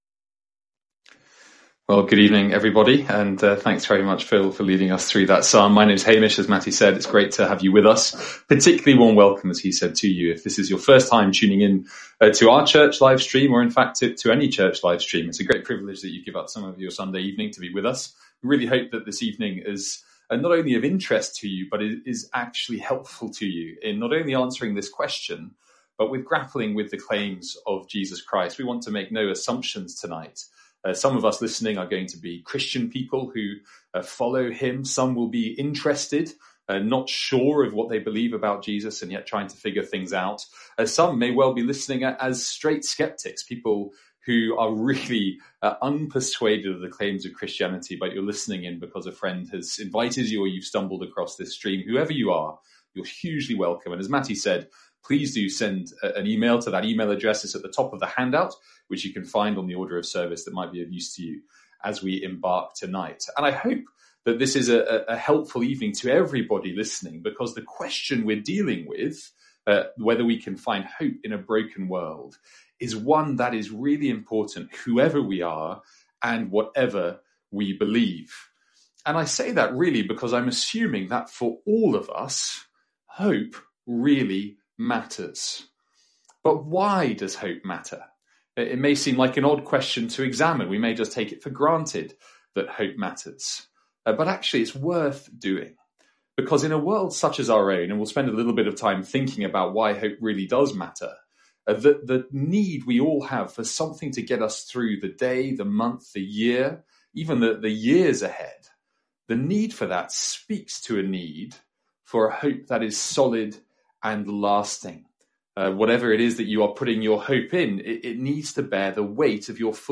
From our 'One Question' guest service.